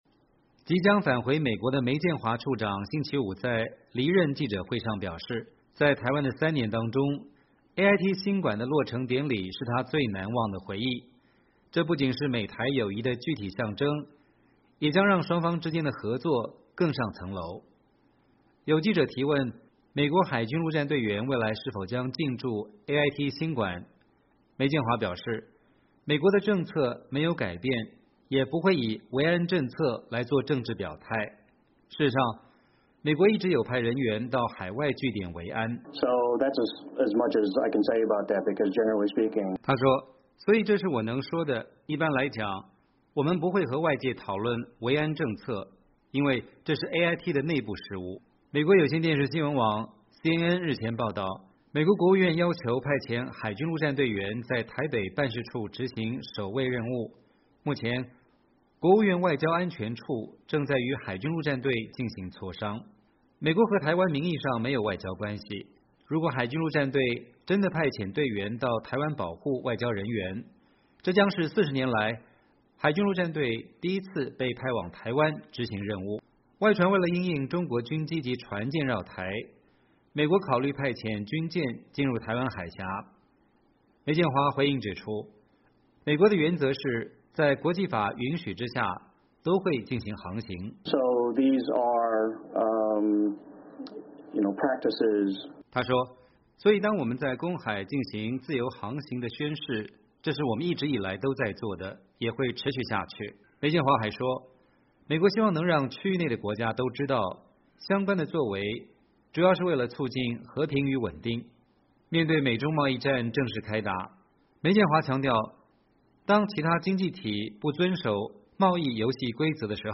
即将返回美国的梅健华处长星期五在离任记者会上表示，在台湾的3年当中，AIT新馆的落成典礼是他最难忘的回忆，这不仅是美台友谊的具体象征，也将让双方之间的合作更上层楼。